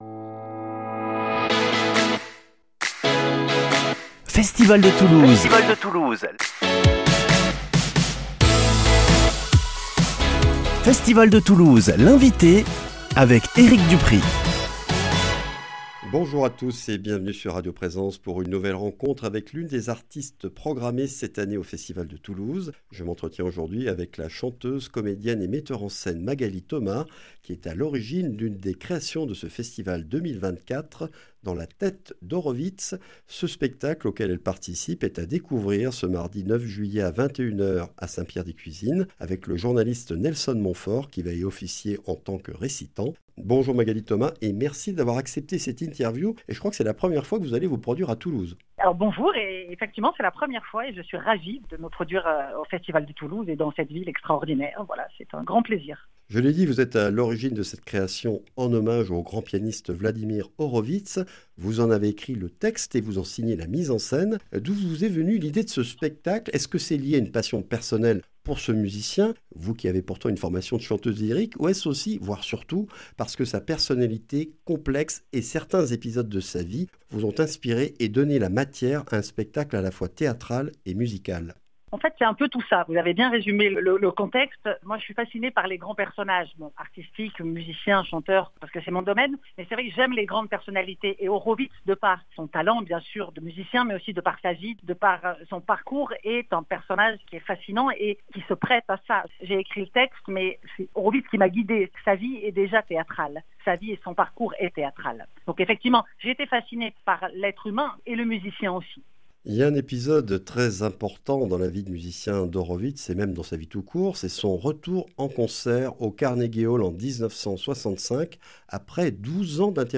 Festival de Toulouse - Interviews